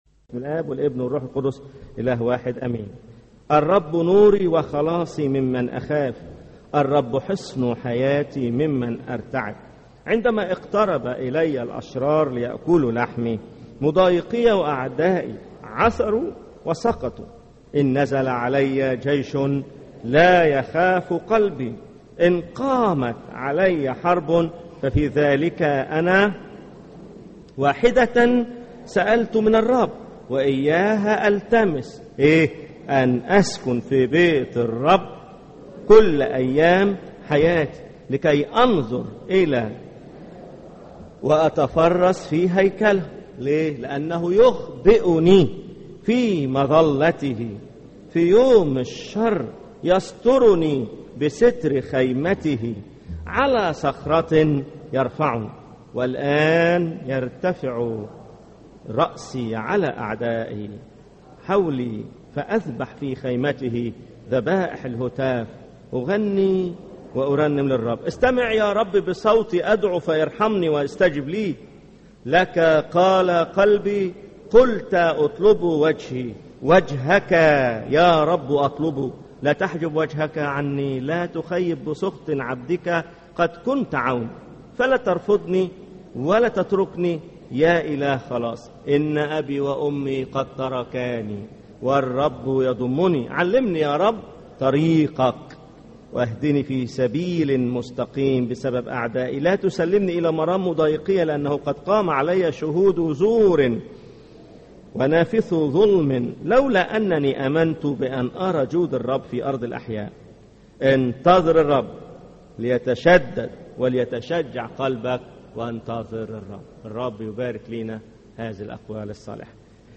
سلسلة عظات لا تخف - 5 - لا تخف لأن الله حصنك